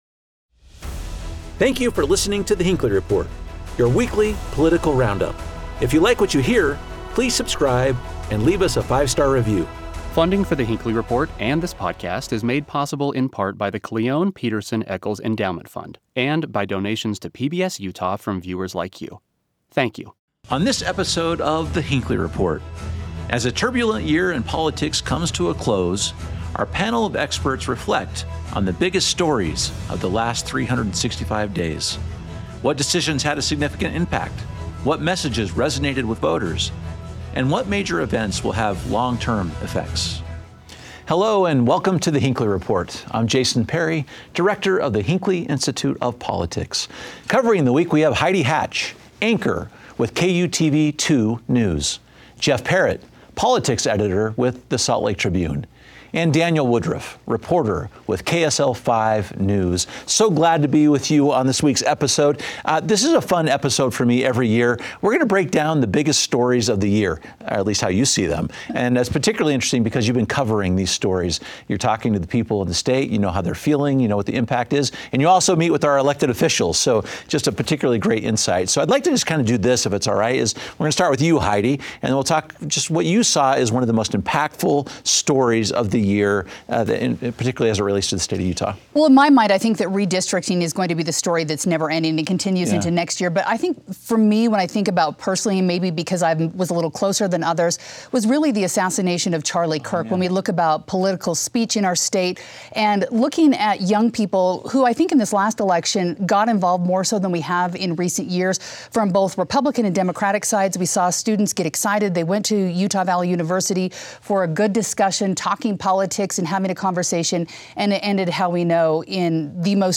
As a turbulent year in politics comes to a close, our panel of experts reflect on the biggest political headlines of the last 365 days. What decisions had a significant impact?